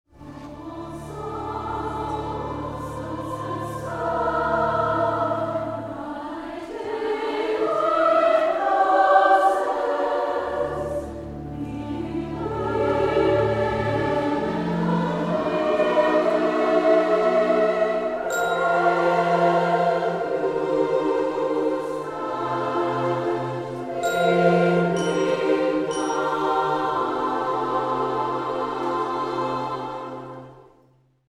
SSAA choir